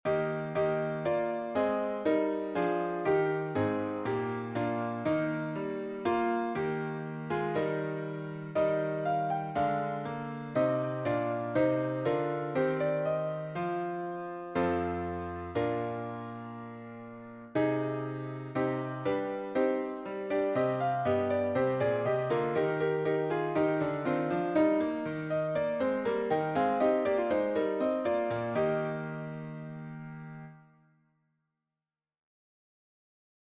Meter: 8.8.8.8
Key: E♭ Major